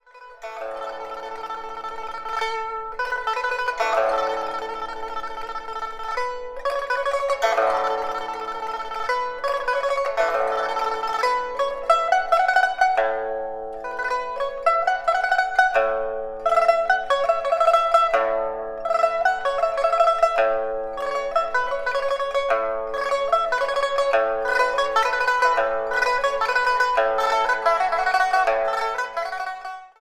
一用四弦唤万马千军，以势摄人
艺术家介绍: *录音时间： 2016年2月26日 中国国家大剧院录音棚